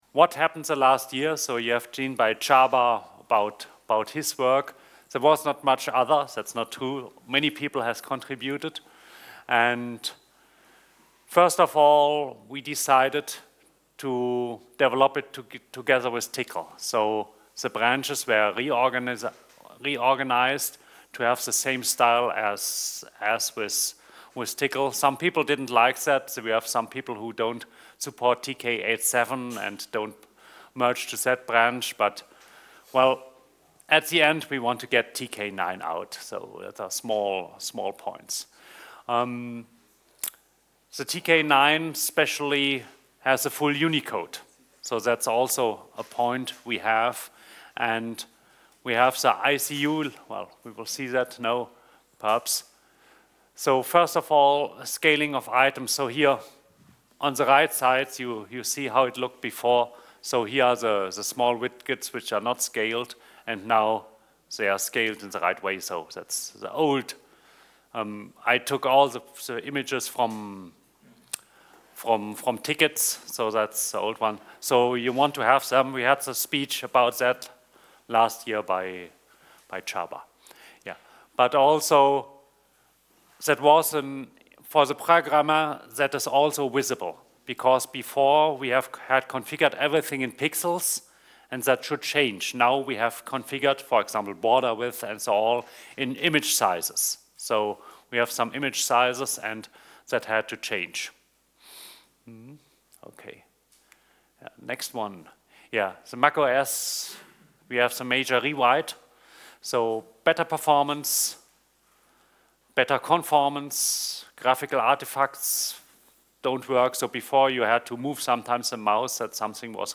Panel 2: State and Future of Tk
OpenACS and Tcl/Tk Conference 2024